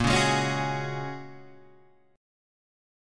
A# Minor 7th Sharp 5th